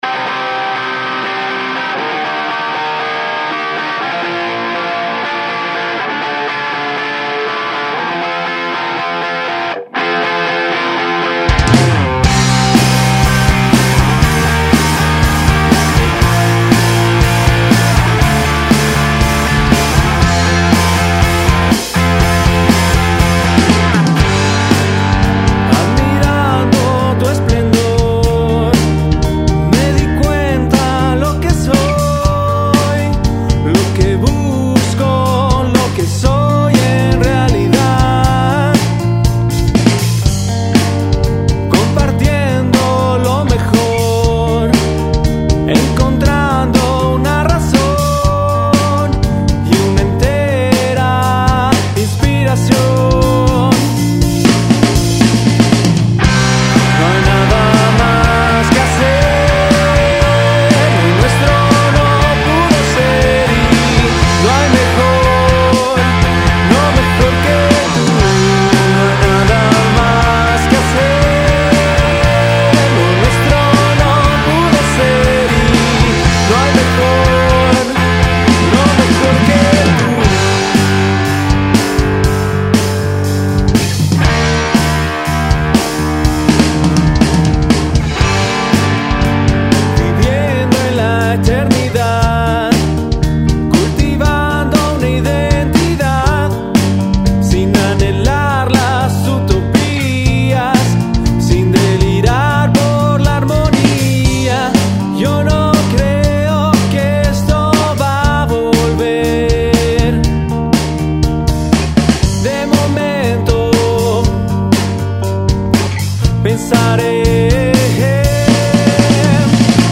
Banda de Rock